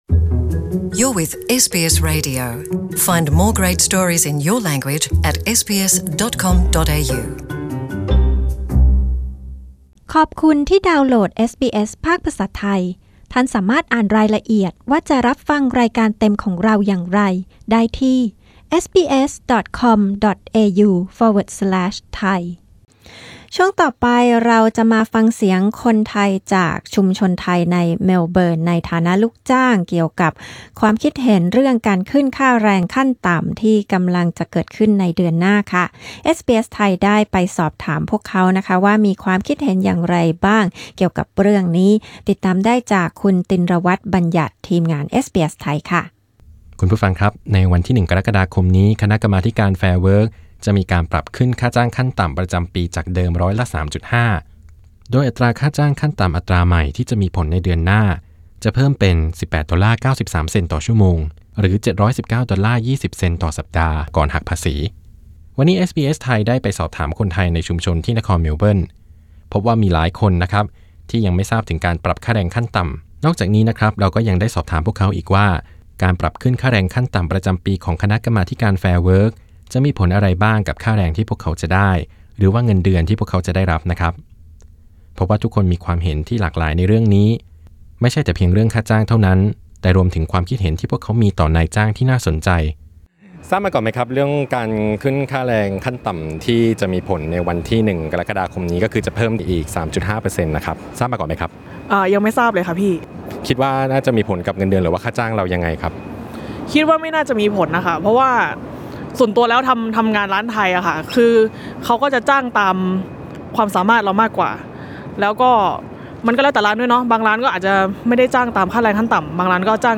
กรกฎาคมนี้ "แฟร์เวิร์ค" ปรับขึ้นค่าแรงขั้นต่ำ เอสบีเอสไทยพูดคุยกับคนไทยในนครเมลเบิร์นถึงข้อคิดเห็น และสิ่งที่พวกเขาจะได้รับในฐานะลูกจ้าง